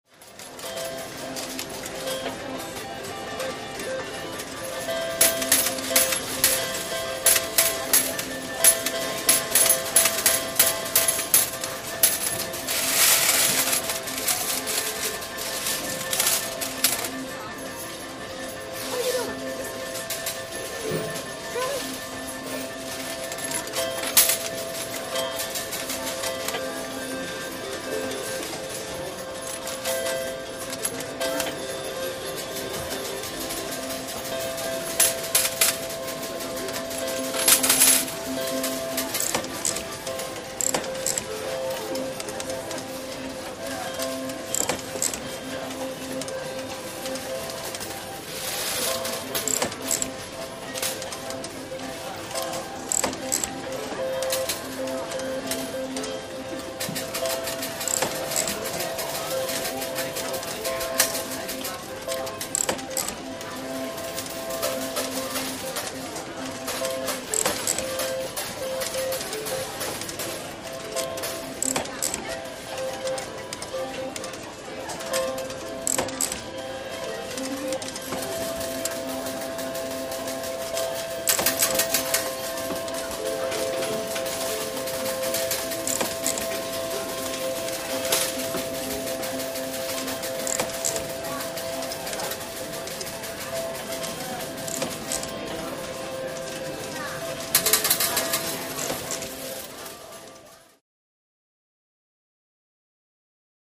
Casino Ambience; Very Busy Electronic Slot Machines, Coin Drops, Cu Pov.